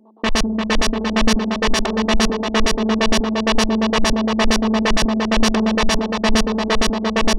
Arpeggio A 130.wav